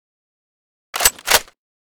bolt.ogg.bak